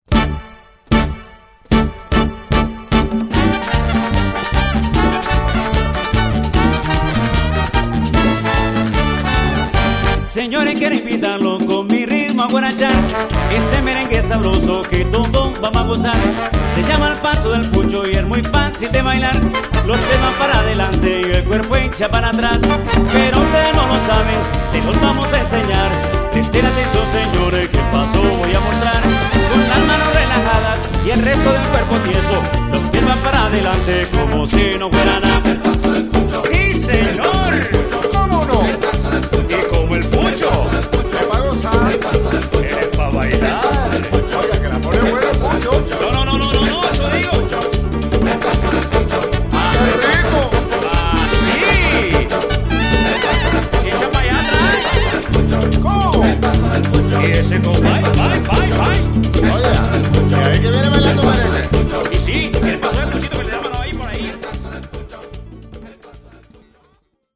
orchestra di salsa, merengue, boleros, cumbia
voce e percussioni
piano
batteria e timbales
congas
tromba
sax tenore